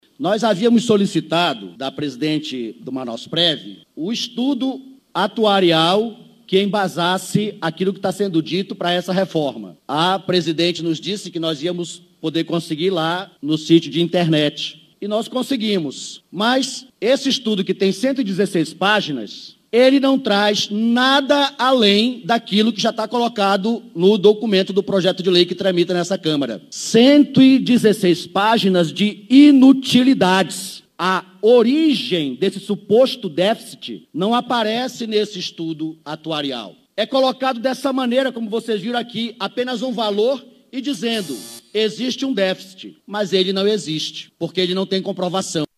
Em fala na tribuna da Câmara